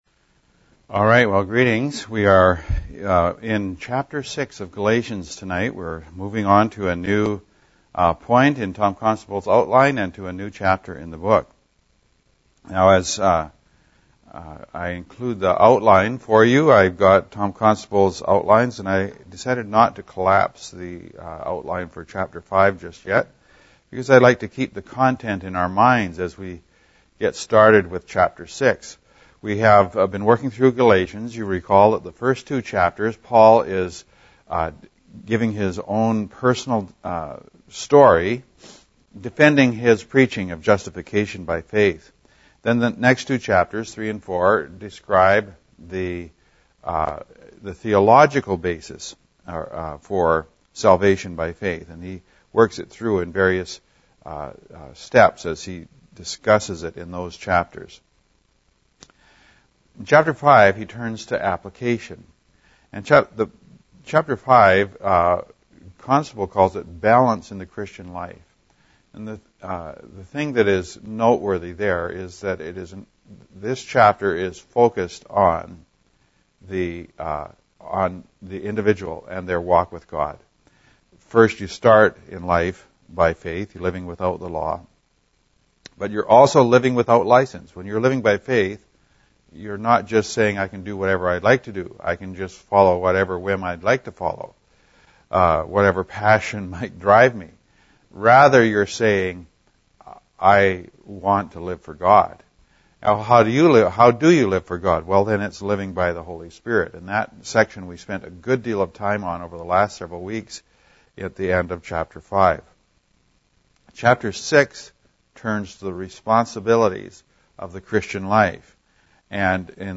Note: Some audio difficulty, so I had to splice together two recordings. Hopefully it isn’t too distracting.